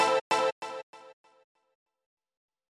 Аккорд.
Помогите пожалуйста накрутить этот тембр. Ноты Аблетон определил как С4, A3, E3, E2.